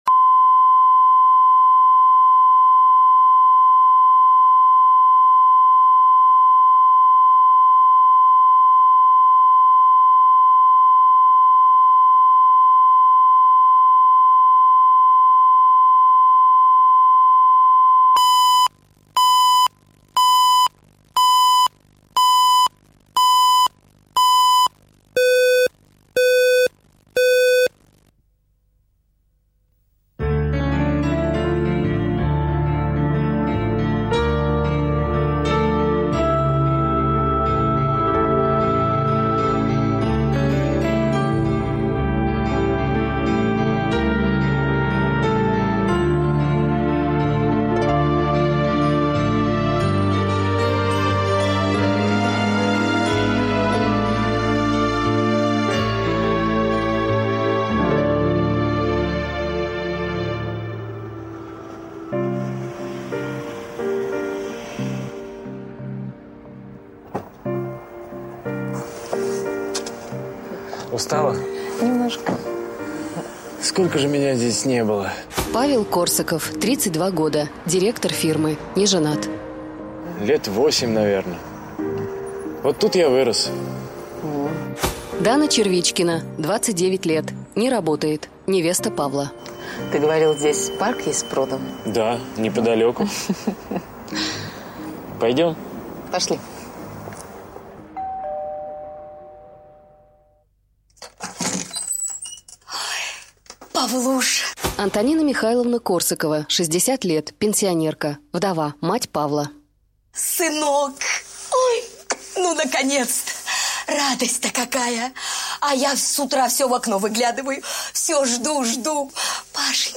Аудиокнига Я тебя люблю | Библиотека аудиокниг